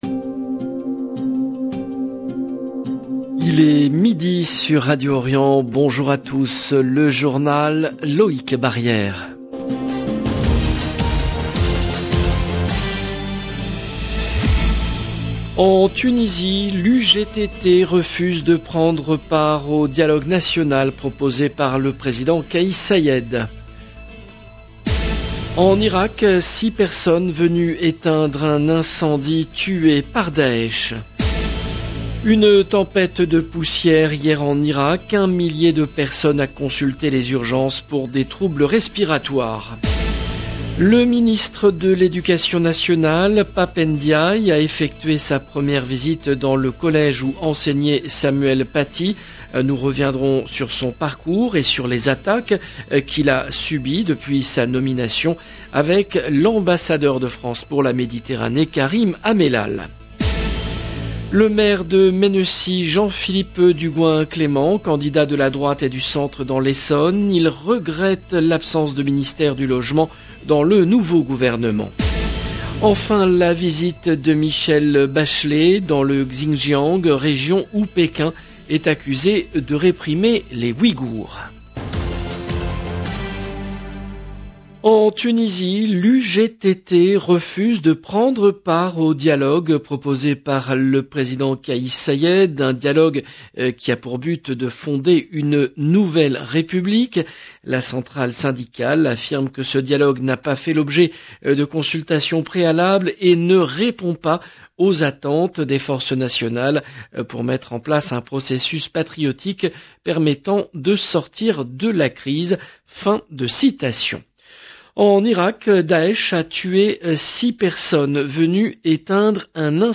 LE JOURNAL EN LANGUE FRANCAISE DE LA MI-JOURNEE DU 24/05/22